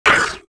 Index of /svencoop/sound/licker
li_pain.wav